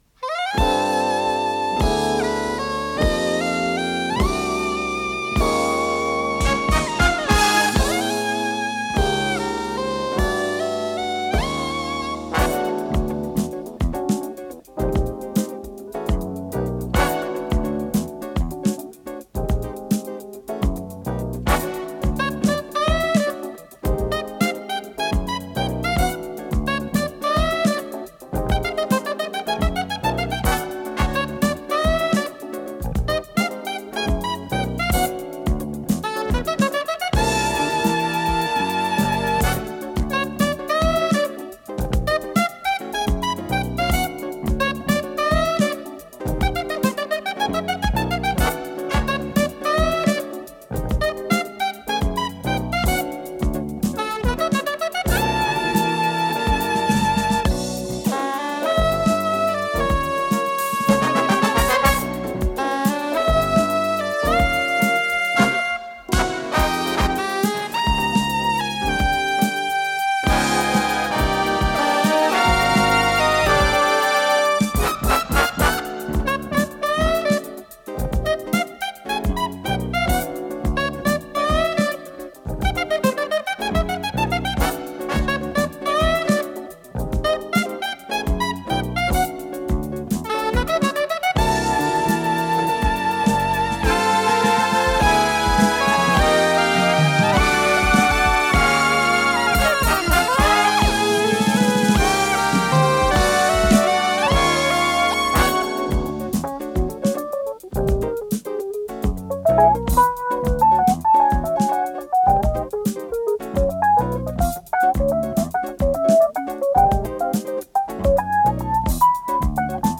с профессиональной магнитной ленты
саксофон-сопрано
ВариантДубль моно